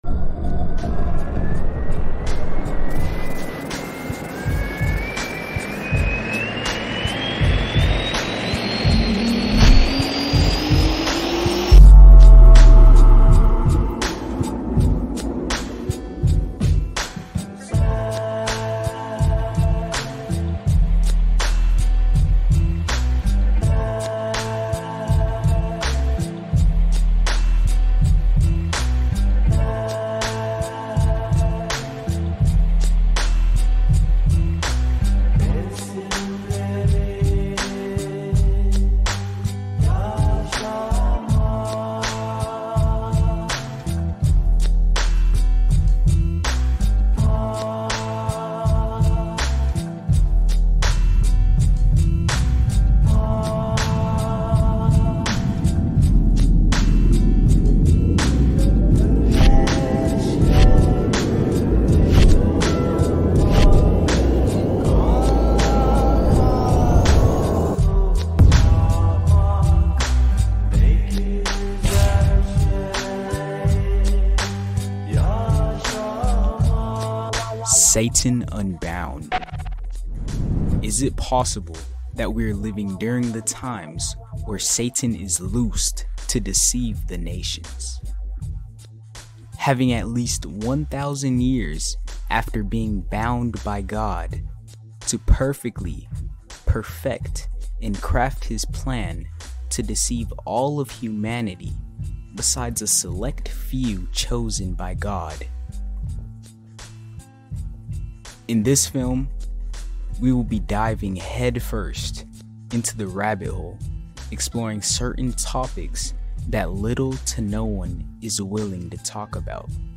Satan Unbound (Full Documentary)